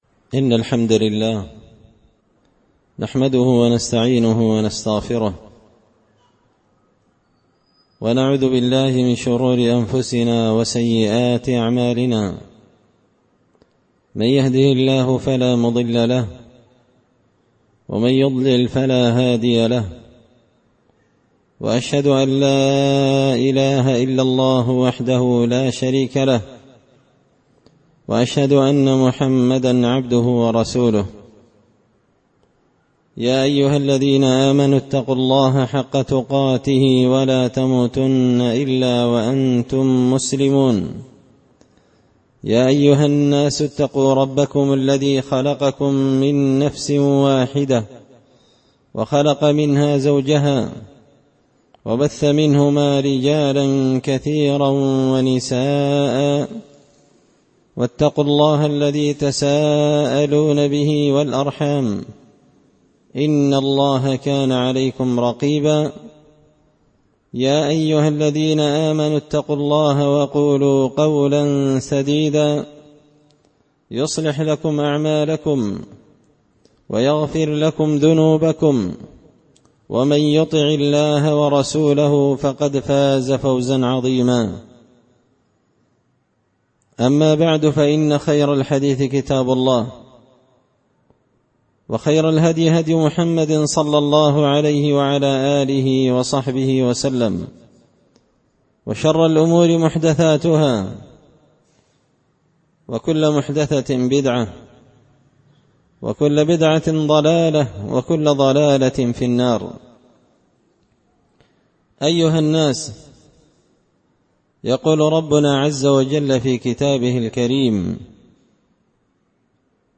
خطبة جمعة بعنوان – حق ذوي القربى
دار الحديث بمسجد الفرقان ـ قشن ـ المهرة ـ اليمن